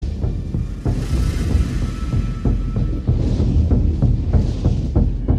走っているときの音に音程はないですが、地の底から来る感じはまさしく「ジュマンジ～ぃ」
JUMANJI - sound effect
最初ウォーキングでゆっくりとしたテンポで始まり、だんだんテンポが速くなりそれにつれて音量も増していくという、、、もうホント JUMANJI (笑)
DRUMS-BEATING-Sound-Effect-1.mp3